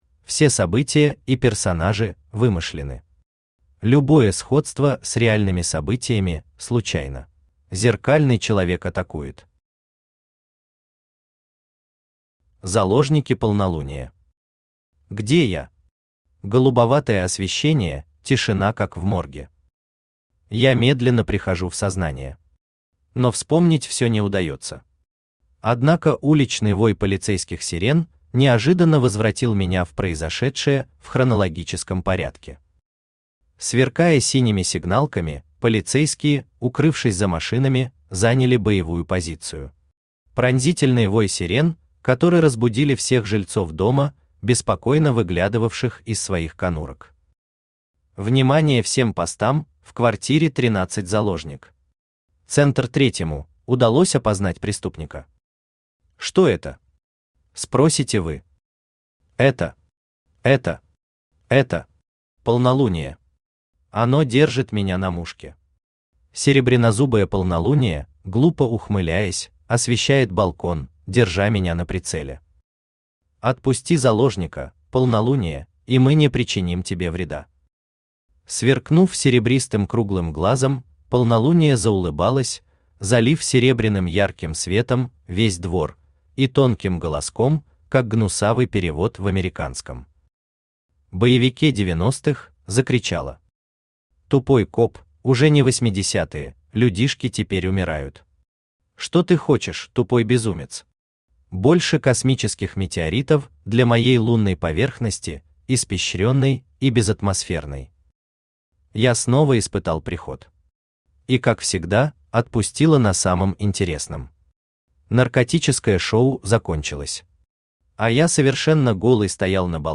Аудиокнига Зеркальный след. Зеркальный человек атакует!
Автор Юрий Павлович Шевченко Читает аудиокнигу Авточтец ЛитРес.